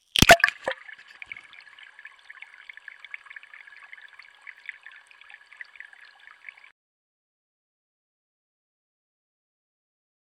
水听器 " 水听器池塘滴水
描述：从Harlaxton庄园的池塘录制的水听器。
Tag: 点滴 池塘 水听器